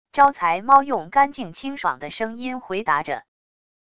招财猫用干净清爽的声音回答着wav音频